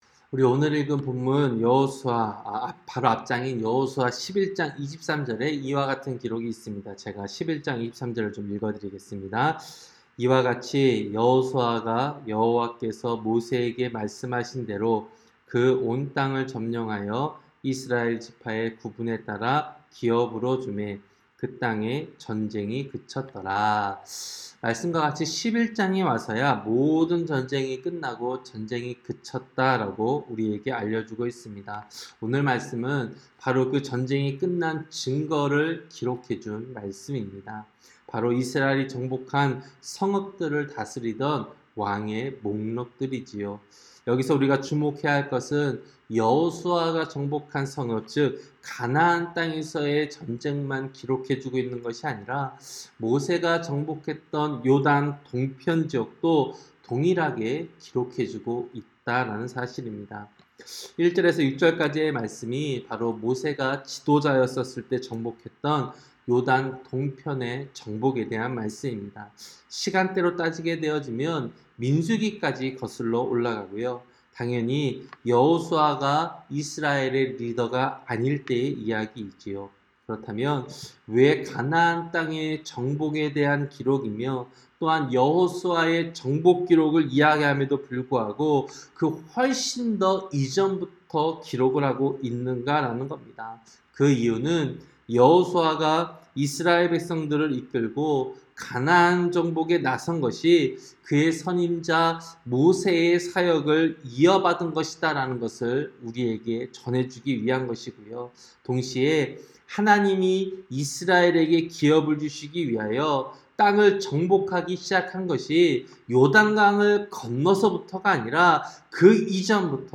새벽기도-여호수아 12장